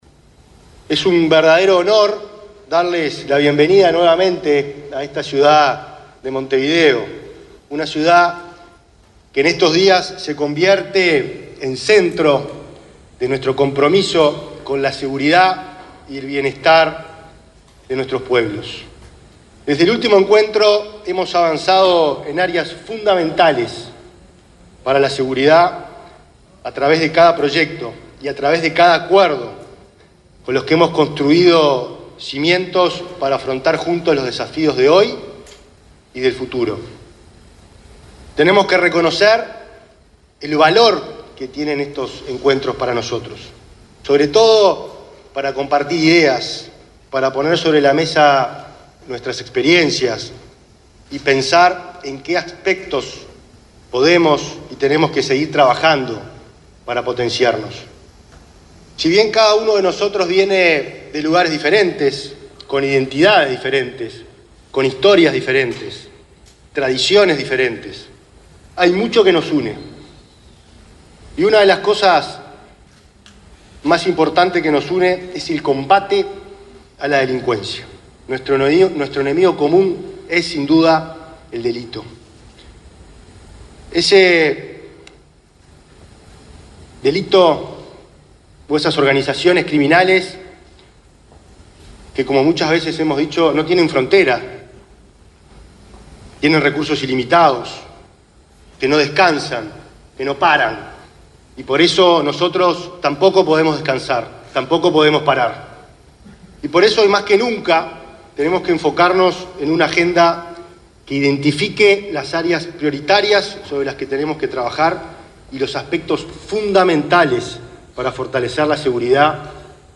Disertación del ministro del Interior, Nicolás Martinelli
El ministro del Interior, Nicolás Martinelli, disertó en la LII Reunión de Ministros del Interior y Seguridad del Mercosur y Estados Asociados, que se